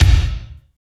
35.02 KICK.wav